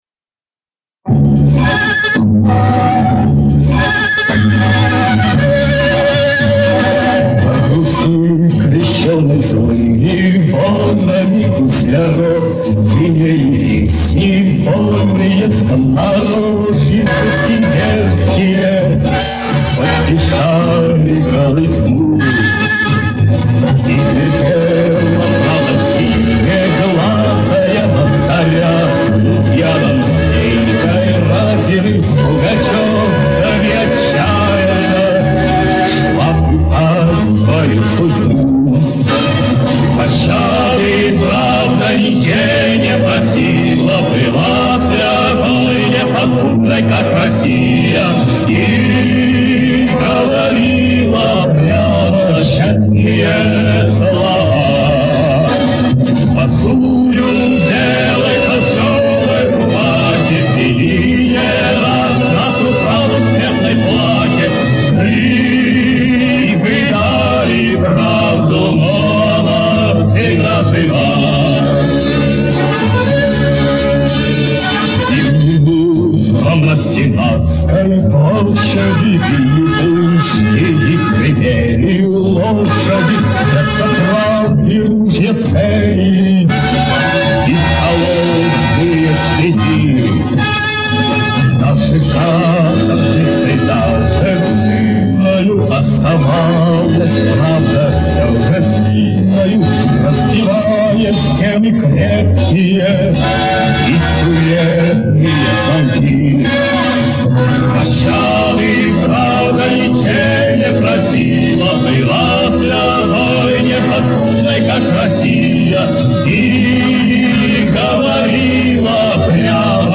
Фазировочку поправил.